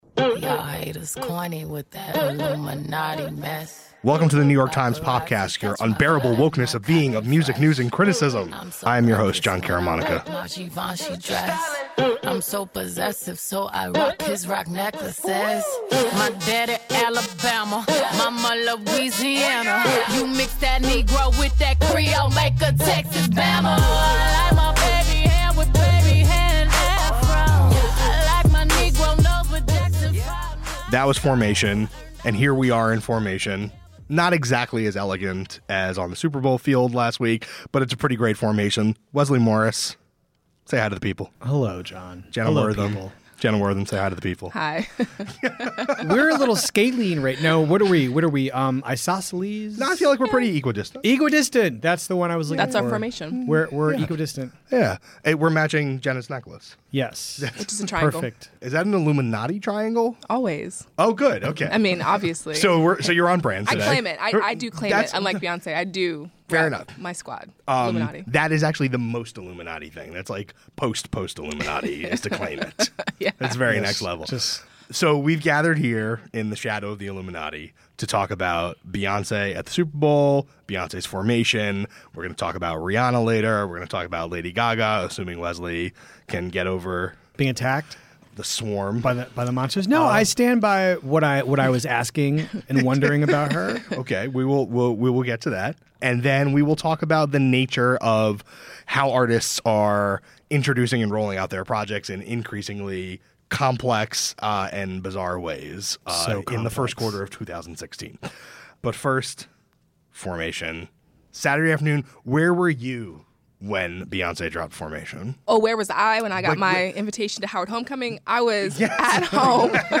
Times critics discuss the recent music releases of each singer.